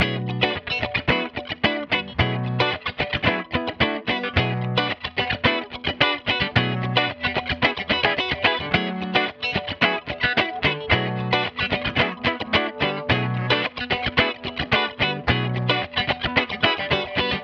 霹雳人》110bpm Bminor
描述：3把吉他 BmFmG
标签： 110 bpm Disco Loops Guitar Electric Loops 1.47 MB wav Key : B
声道立体声